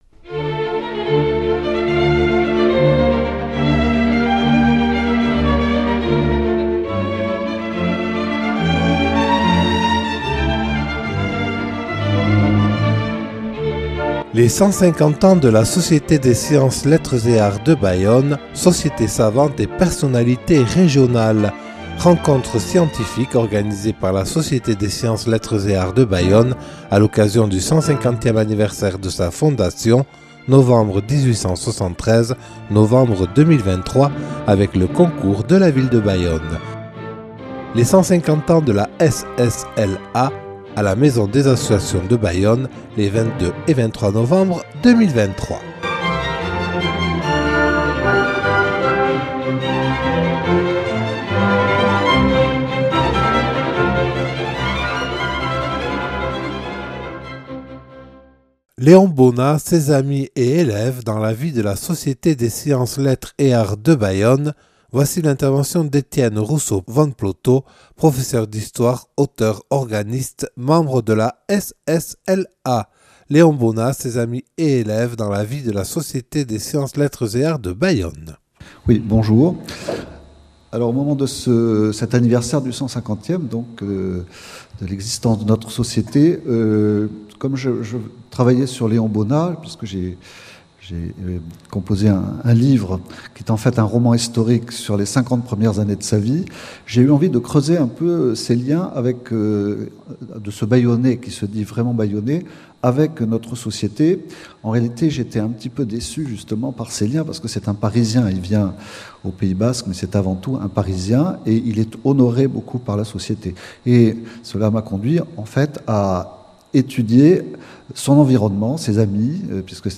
Les 150 ans de la Société des Sciences, Lettres et Arts de Bayonne – (2) – Rencontre scientifique des 22 et 23 novembre 2023